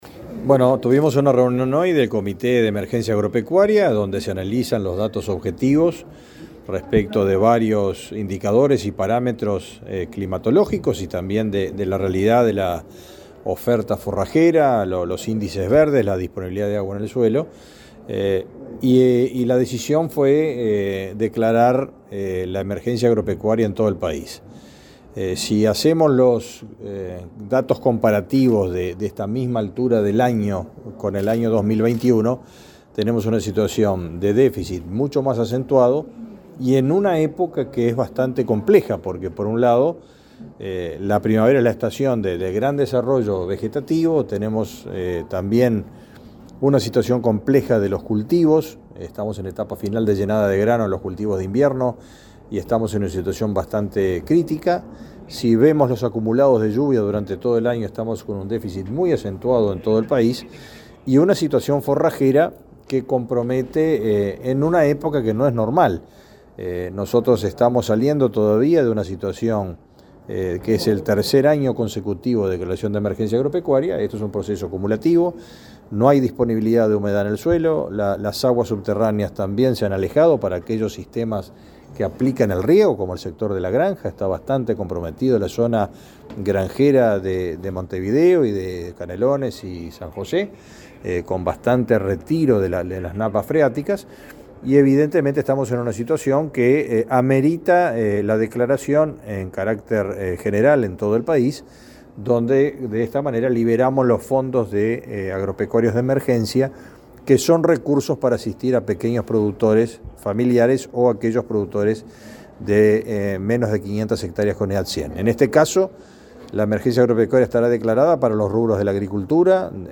Declaraciones del ministro de Ganadería, Fernando Mattos
El ministro de Ganadería, Fernando Mattos, informó a la prensa acerca de la declaración de emergencia agropecuaria en 16 millones de hectáreas.